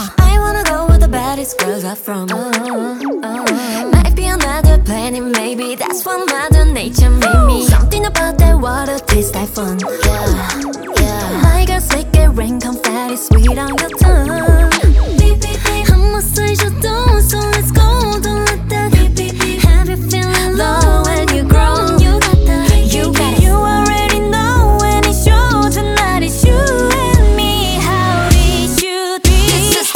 K-Pop
2025-07-11 Жанр: Поп музыка Длительность